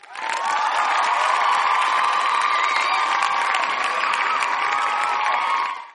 Clap Sound ringtone free download
Sound Effects